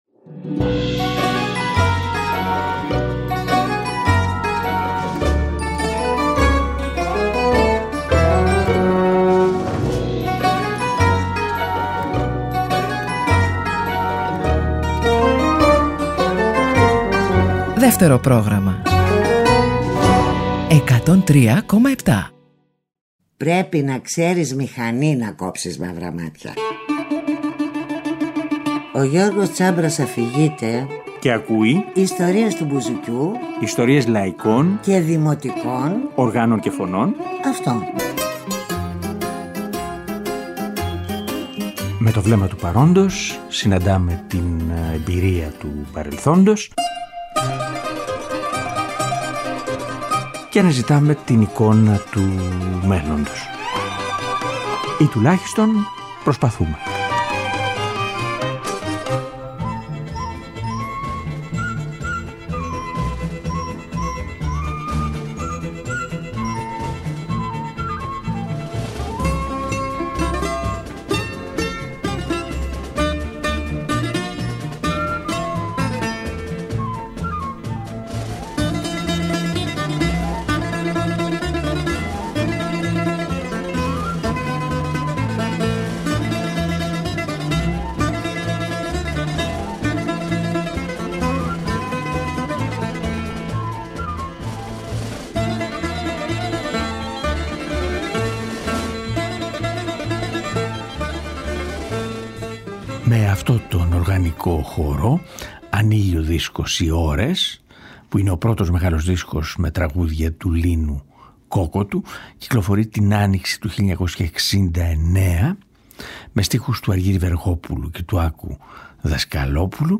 Ωστόσο, το μεγαλύτερο μέρος του υλικού του, έχει να κάνει με λαϊκούς και δημοτικούς ρυθμούς και πολύ νωρίς το μπουζούκι κρατά κεντρικό ρόλο στα τραγούδια του.